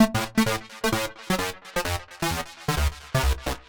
Index of /musicradar/uk-garage-samples/130bpm Lines n Loops/Synths